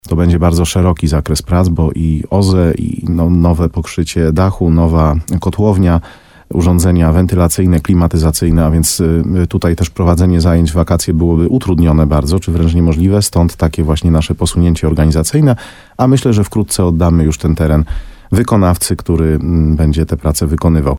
Wójt Mariusz Tarsa w programie Słowo za Słowo na antenie RDN Nowy Sącz podkreślał, że liczy na rozstrzygnięcie przetargu w przyszłym tygodniu.